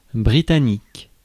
Ääntäminen
Ääntäminen France: IPA: [bʁi.ta.nik] Haettu sana löytyi näillä lähdekielillä: ranska Käännös Ääninäyte Adjektiivit 1.